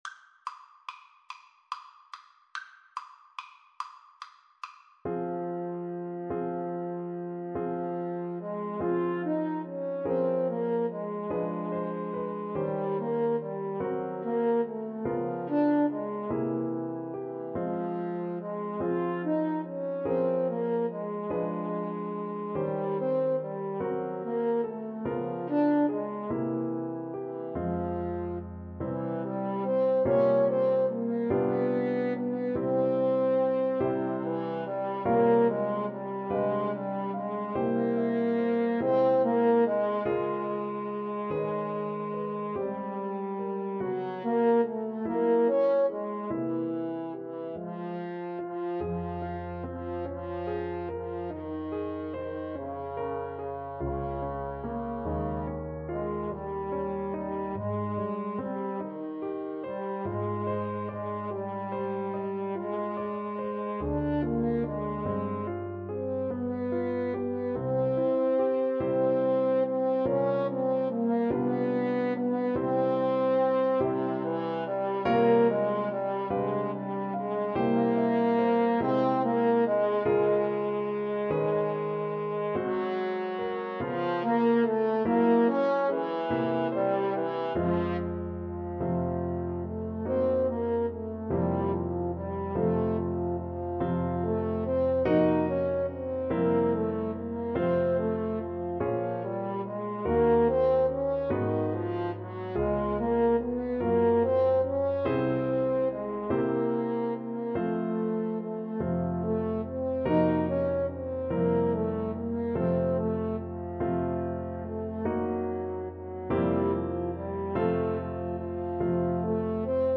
French Horn 1French Horn 2
6/8 (View more 6/8 Music)
. = 48 Andante quasi allegretto
Classical (View more Classical French Horn Duet Music)